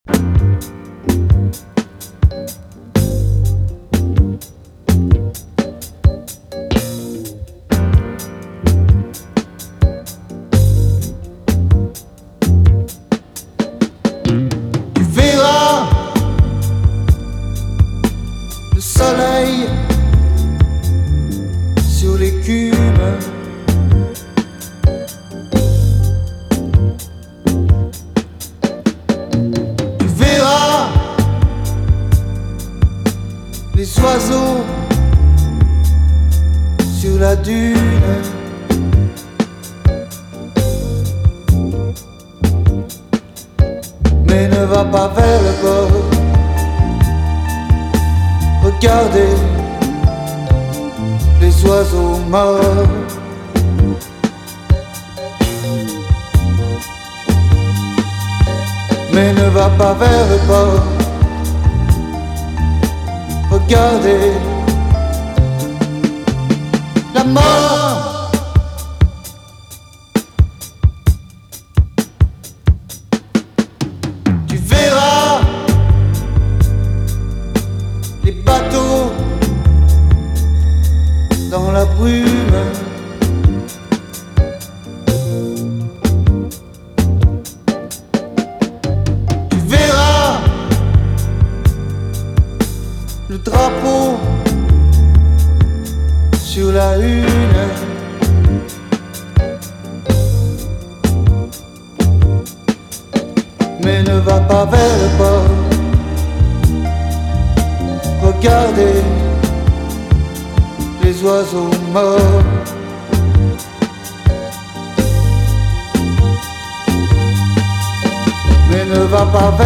funk and soul singles